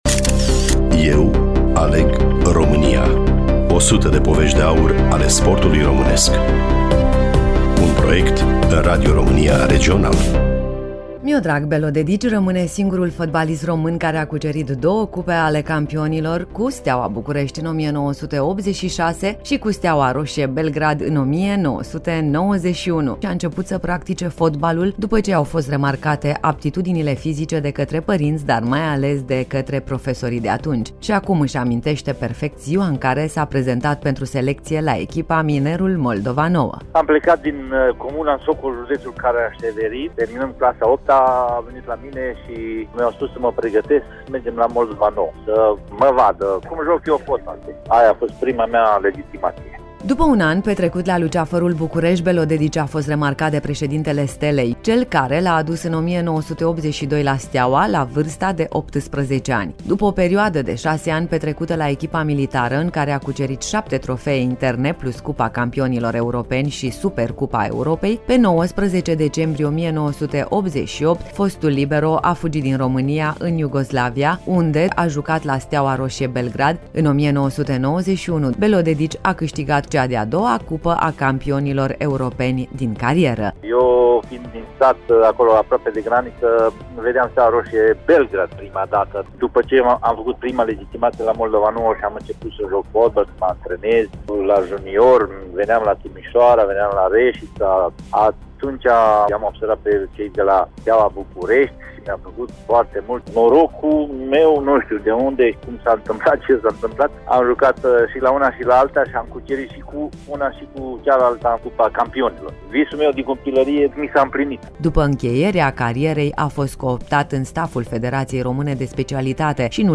Studioul: Bucuresti FM